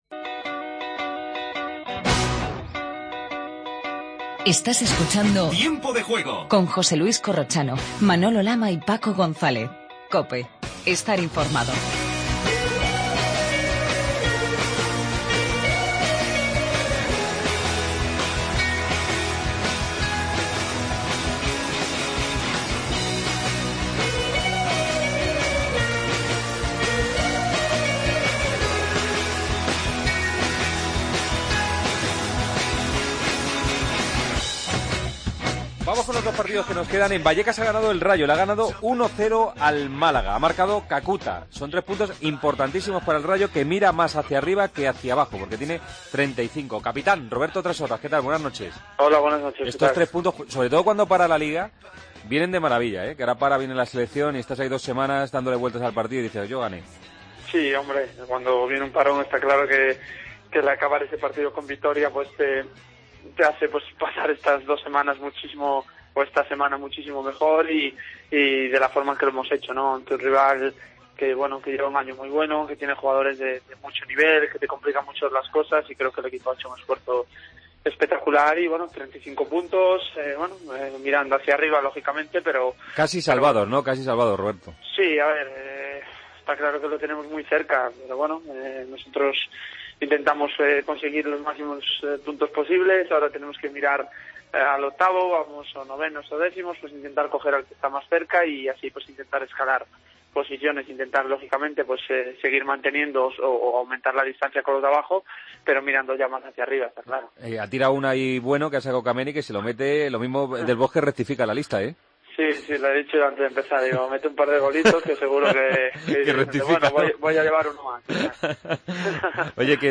Redacción digital Madrid - Publicado el 22 mar 2015, 01:54 - Actualizado 13 mar 2023, 22:33 1 min lectura Descargar Facebook Twitter Whatsapp Telegram Enviar por email Copiar enlace Previas de la jornada de Liga del domingo. Escuchamos a Trashorras y Charles, tras las victorias de Rayo y Celta.